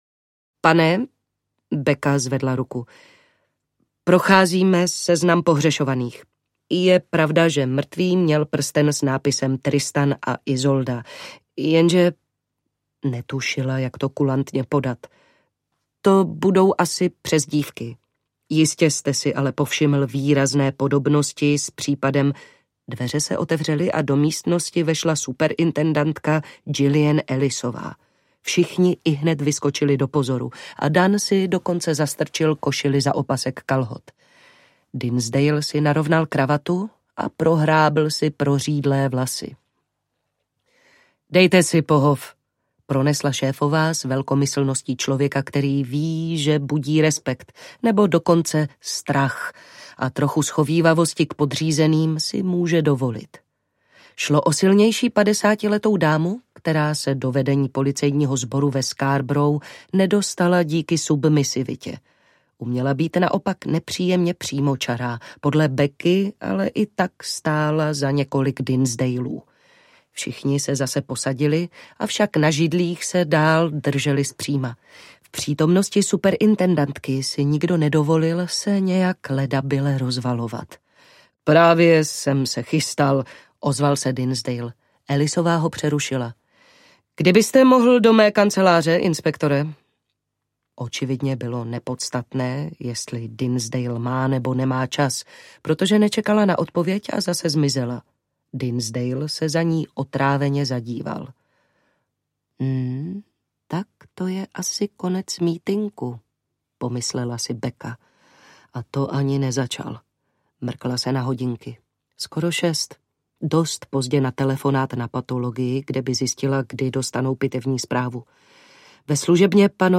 Ukázka z knihy
krajina-smrti-audiokniha